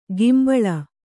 ♪ gimbaḷa